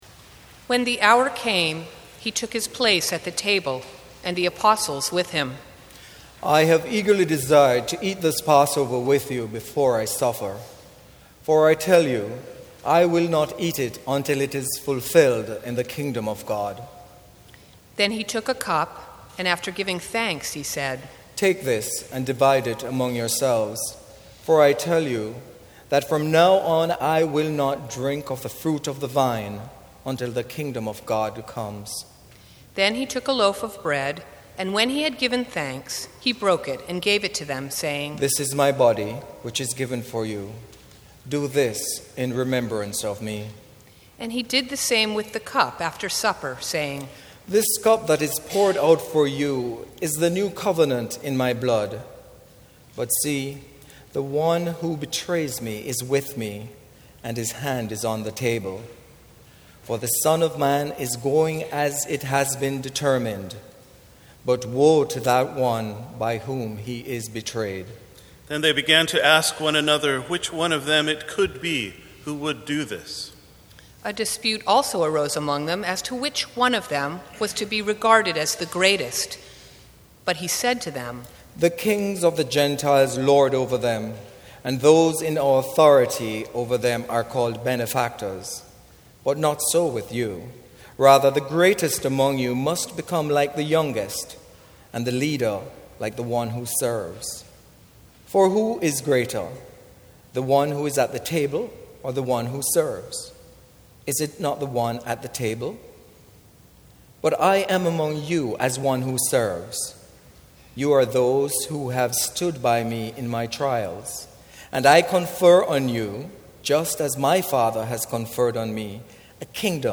A dramatic Reading of The Passion of Our Lord According to Luke